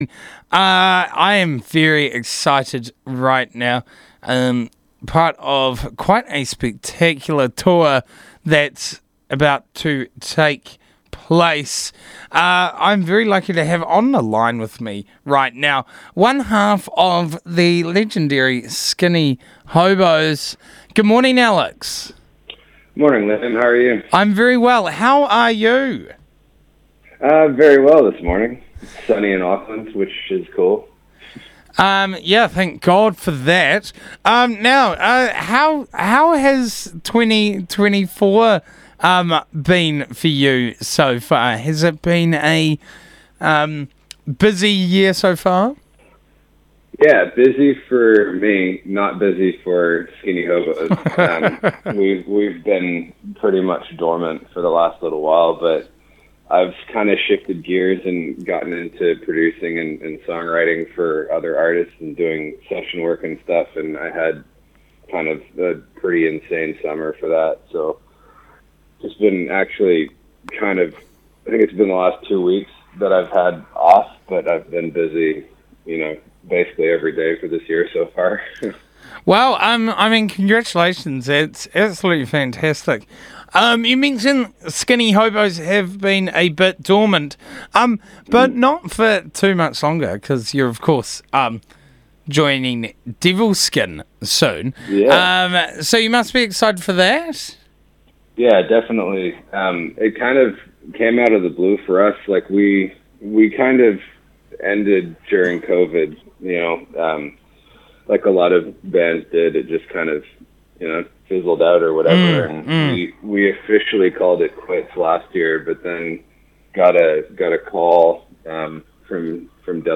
dialled into the Burnt Breakfast show to talk about their upcoming tour with Devilskin & Tadpole.